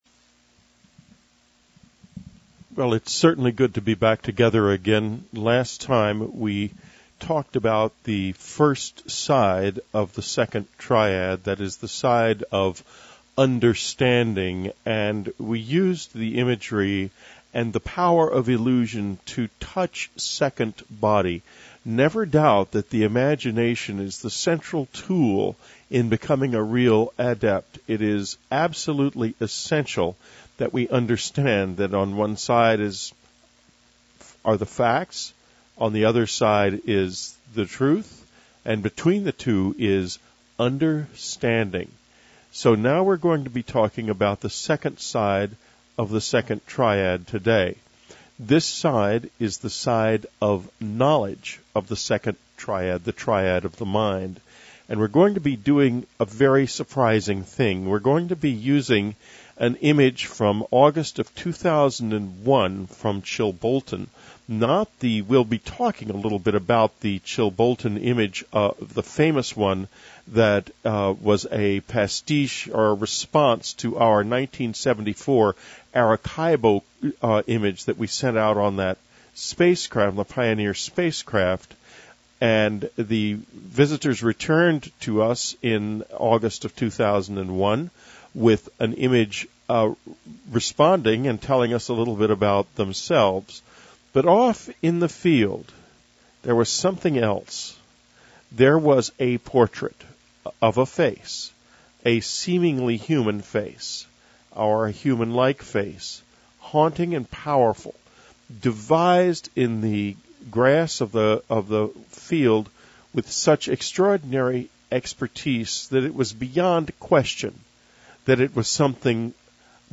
Whitley Strieber offers for our subscribers the fifth in his series of nine crop circle meditations. This one deals with side two of the second triad, the triad of the mind.
Listen as Whitley takes us down the road to a kind of inner knowledge that we seldom touch, and we use the power of one of the crop circles to help us go even deeper on our own.